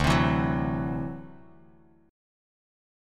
D9 Chord
Listen to D9 strummed